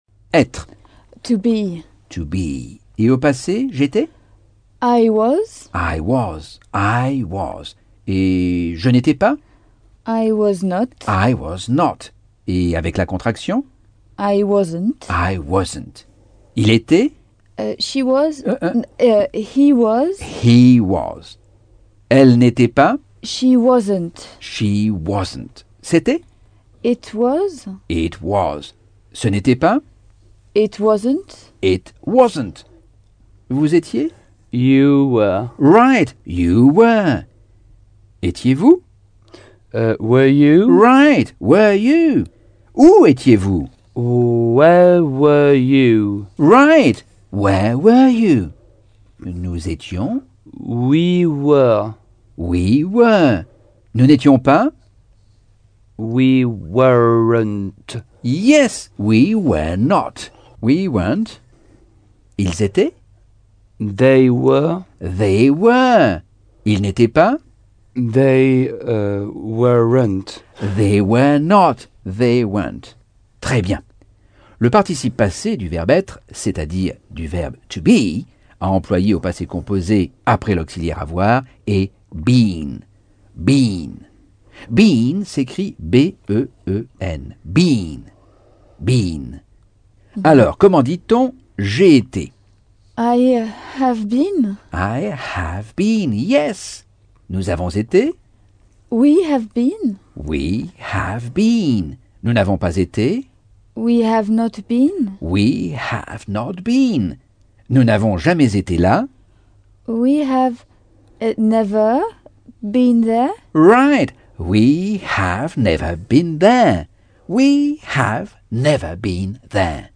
Leçon 10 - Cours audio Anglais par Michel Thomas - Chapitre 8